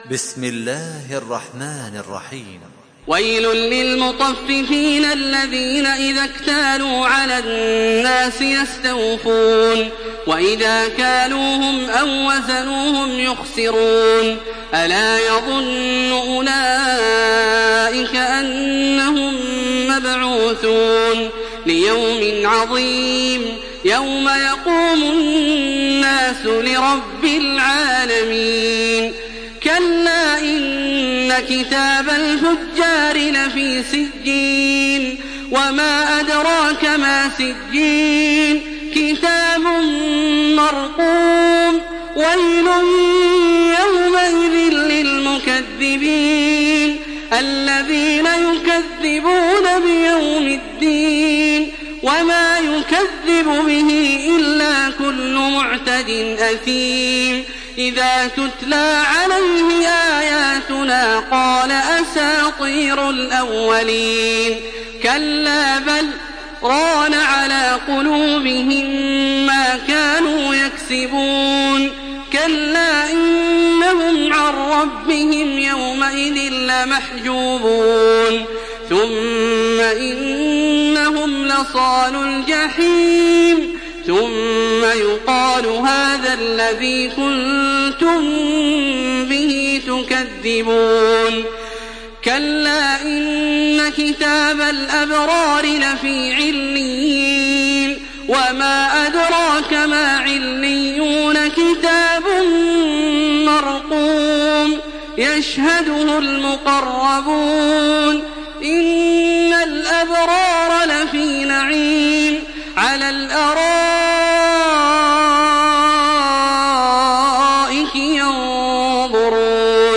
Surah Al-Mutaffifin MP3 in the Voice of Makkah Taraweeh 1427 in Hafs Narration
Surah Al-Mutaffifin MP3 by Makkah Taraweeh 1427 in Hafs An Asim narration.
Murattal